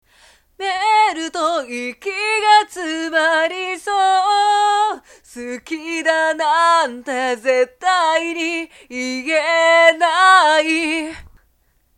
まずは通常のボーカルトラックを用意します。
♪効果はなにもかかっていない状態（アカペラ）